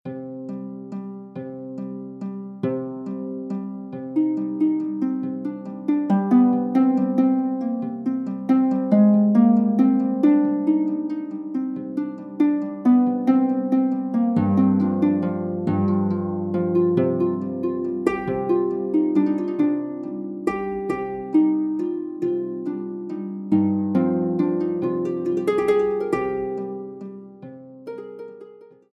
Music for an Imaginary Harp